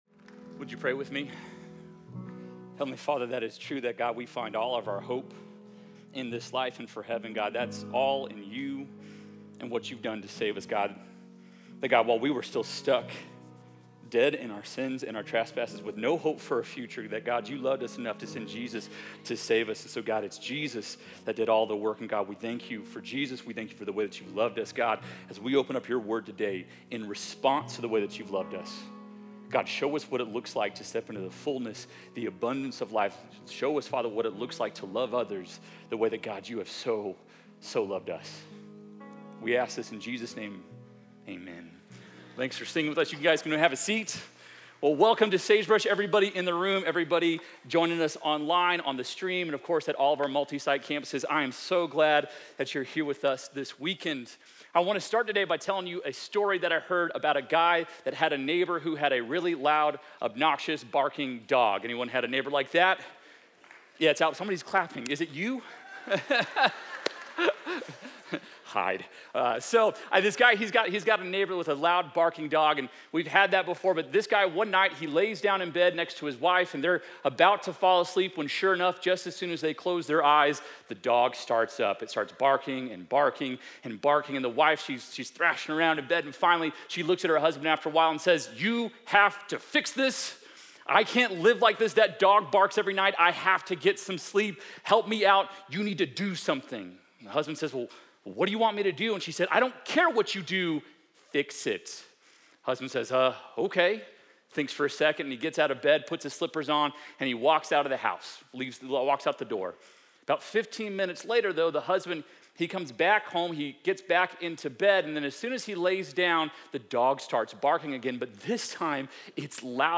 Sermons by Sagebrush Church